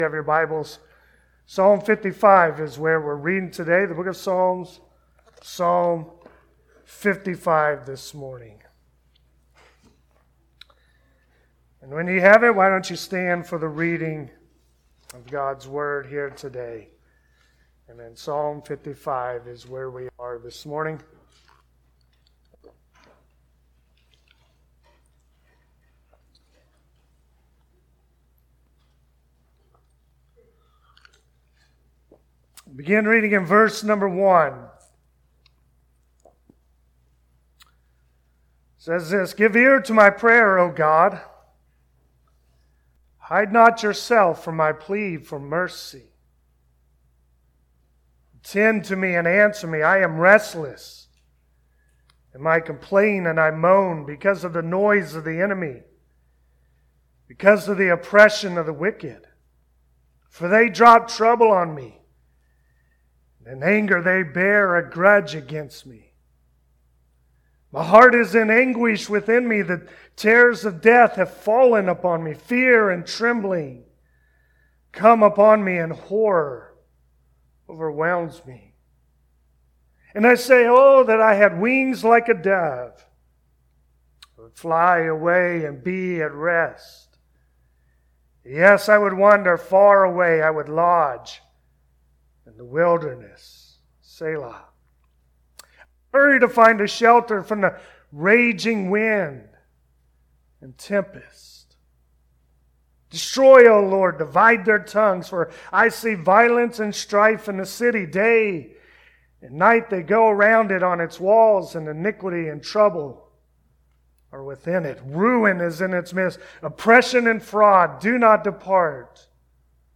Passage: Psalm 55 Service Type: Sunday Morning Psalm 55 reminds us that God is able to sustain us through the trials and difficulties of life as we cast our burdens upon Him.